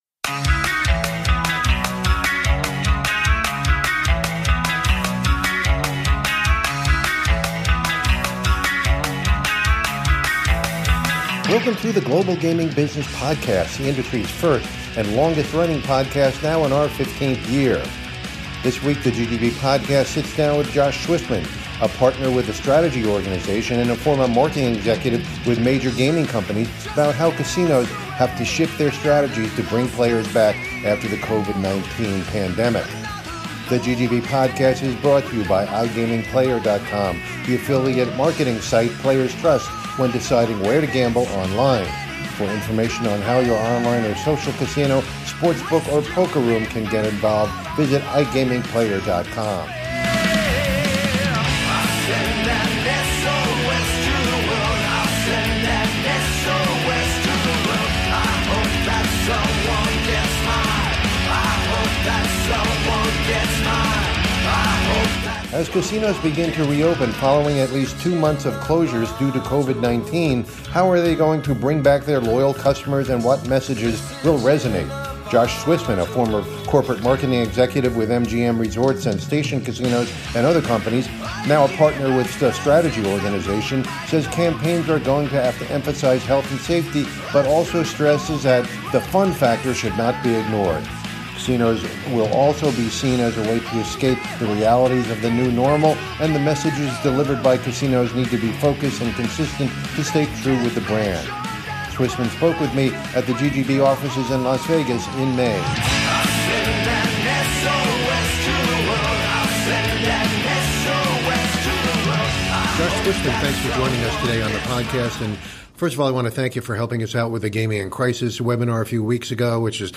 at the GGB offices in Las Vegas in May.